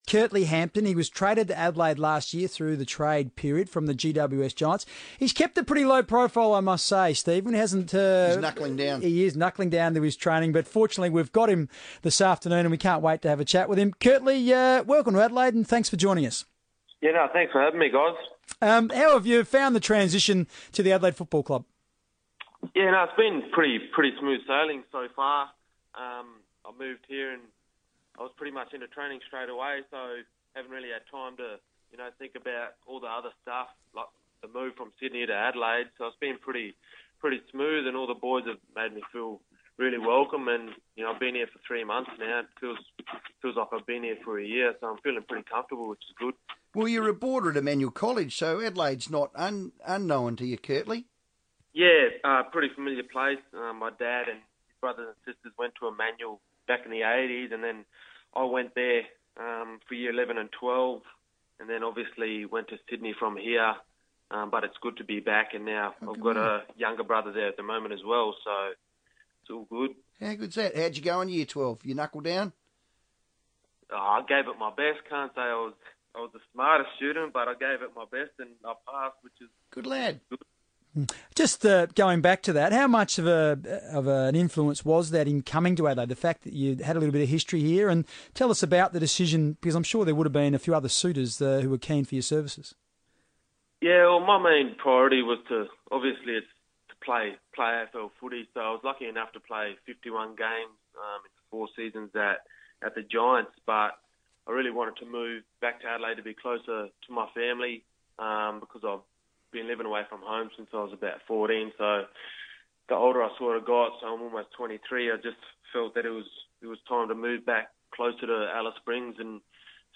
Curtly Hampton joined the FIVEaa Sports Show as Adelaide's pre-season continues to heat up with the Club's first NAB Challenge match only a month away